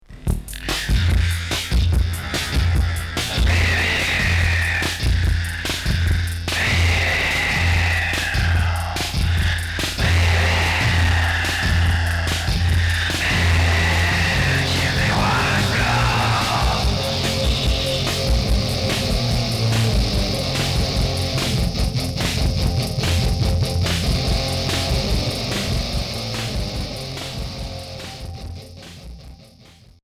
Punk industriel